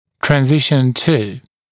[træn’zɪʃn tuː][трэн’зишн ту:]переход к…